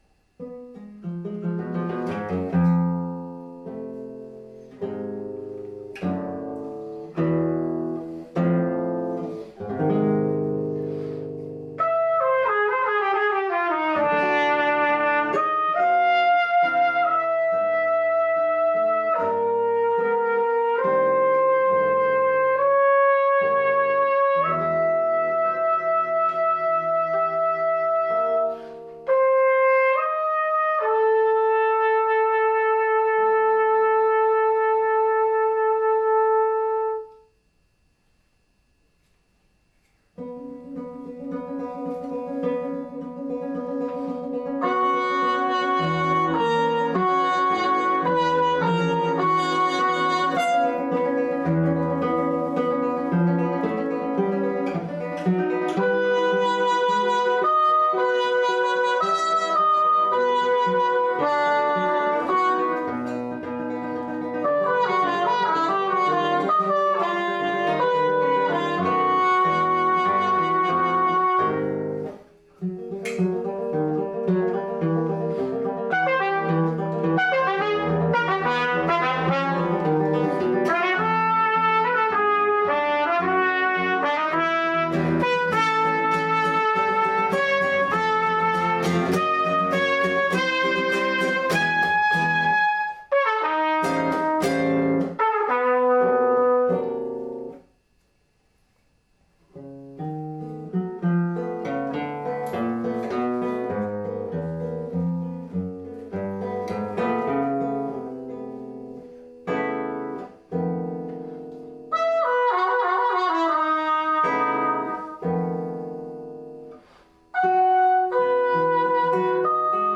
Night Music (for trumpet/guitar, 2020) SCORE |